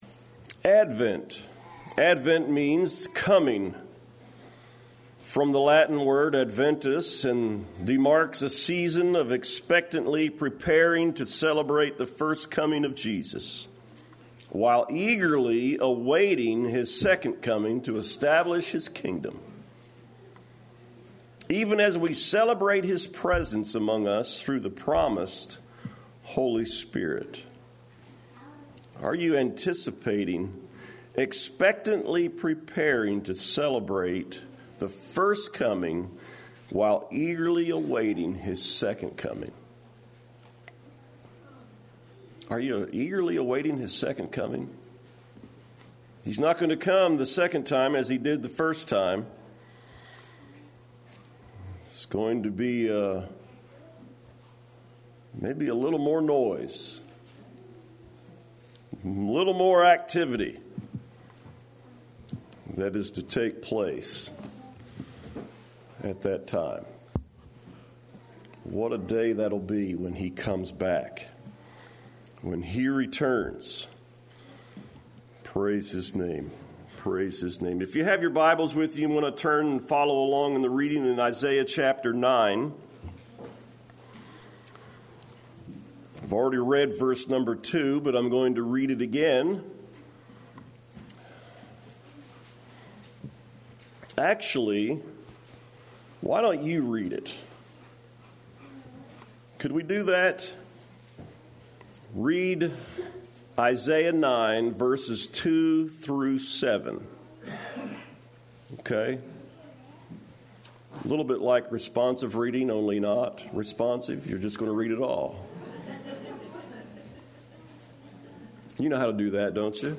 Save Audio The first sermon